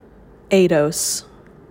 Eidos-Pronunciation.m4a